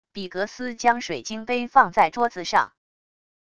比格斯将水晶杯放在桌子上wav音频